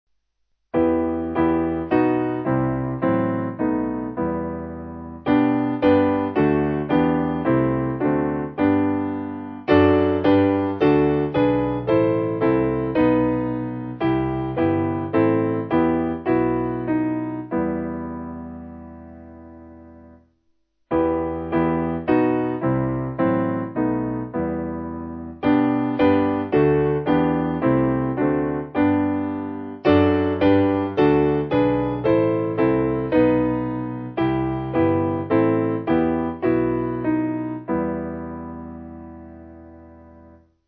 Simple Piano
(CM)   3/Em